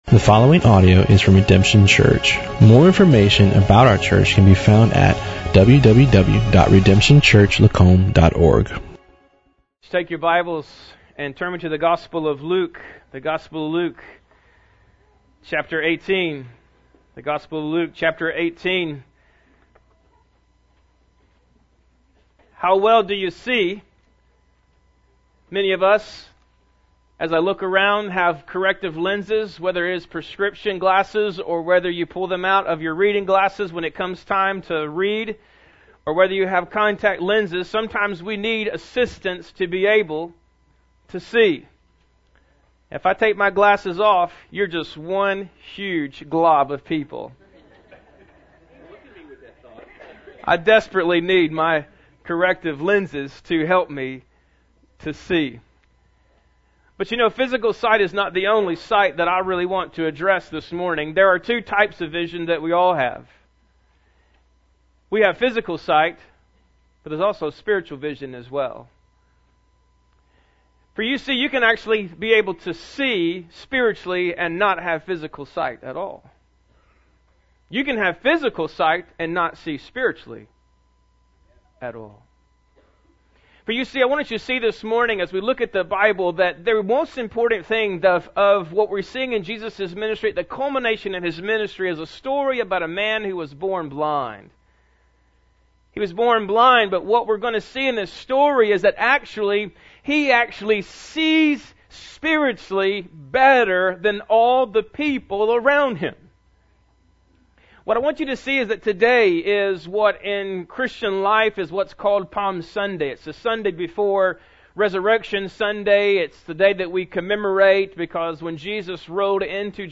Bible Text: Luke 18:35-43 | Preacher